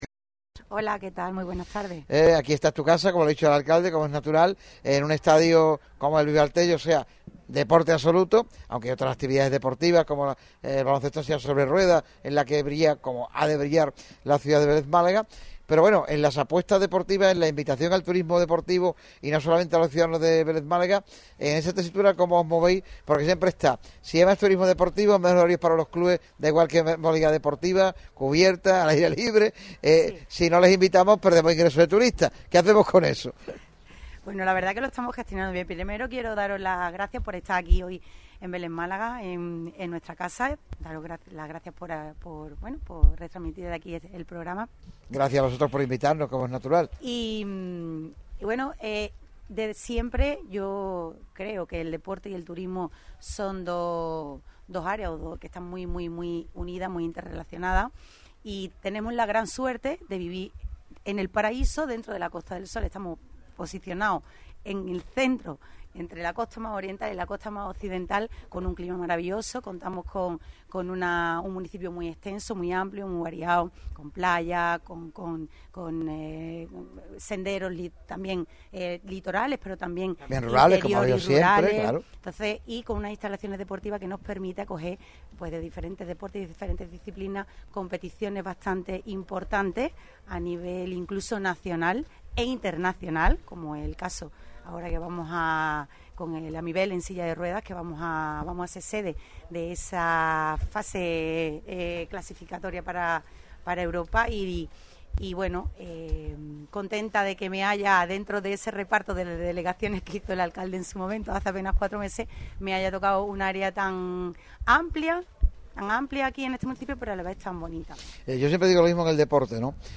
El teniente de alcalde de La Caleta de Vélez-Málaga ha sido uno de los protagonistas en el programa especial de Radio MARCA Málaga realizado en el Estadio Vivar Téllez. David Segura hace hincapié en el crecimiento paulatino de la ciudad y especialmente con el desarrollo del Puerto Pesquero.